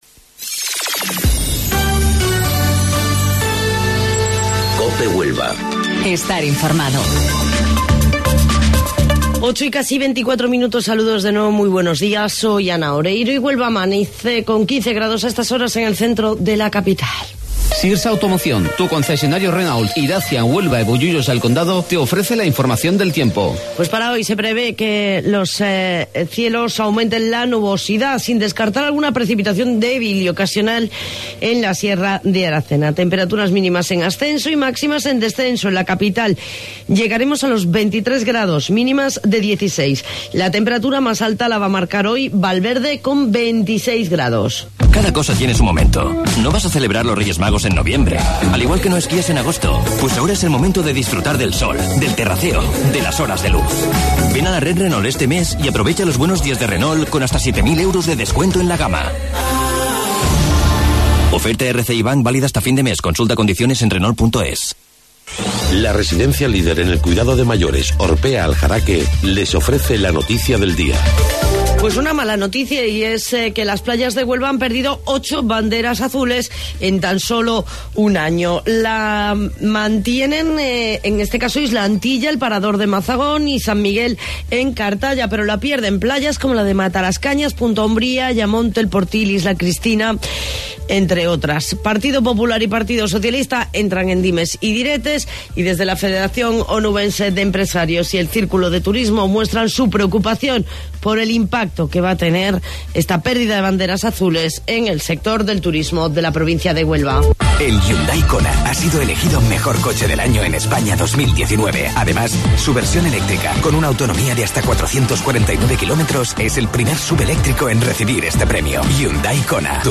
AUDIO: Informativo Local 08:25 del 8 de Mayo